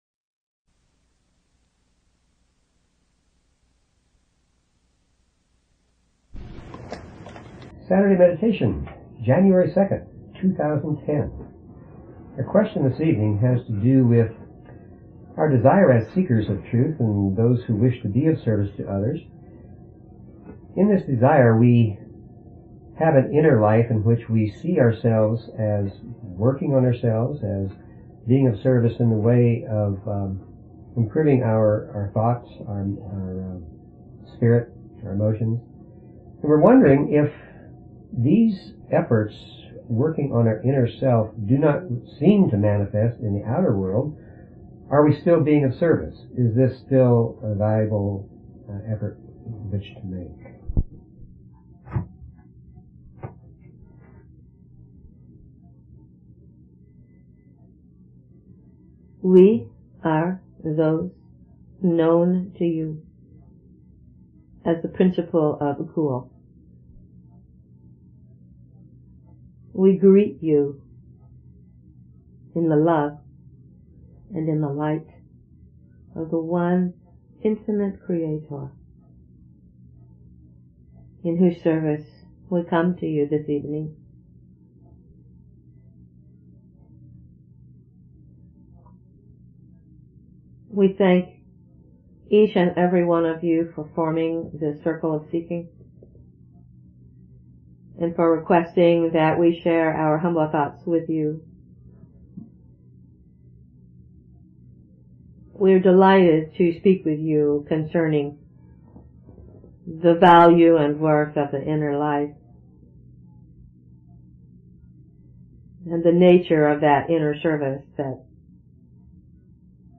/llresearchquocommunications#archives Paranormal Philosophy Physics & Metaphysics Spiritual Medium & Channeling 0 Following Login to follow this talk show LL Research Quo Communications